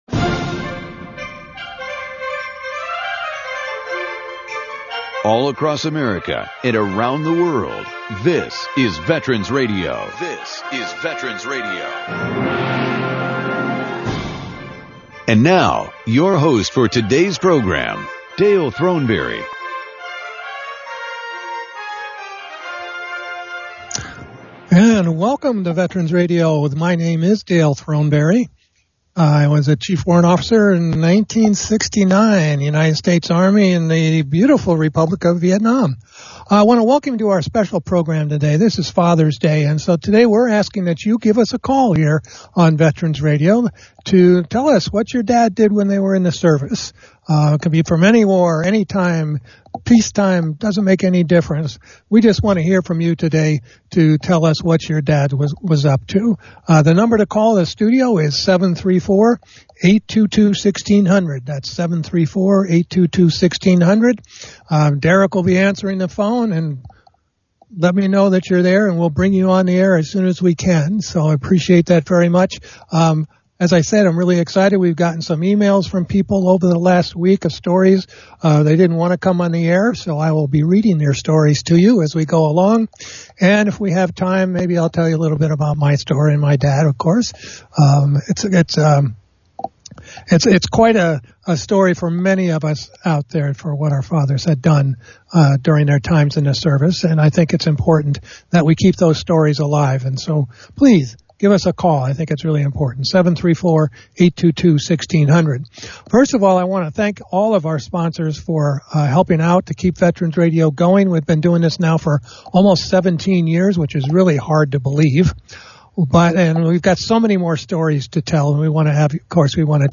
Our live program will be broadcast on Sunday, June 21, 2020 at 5:00pm ET.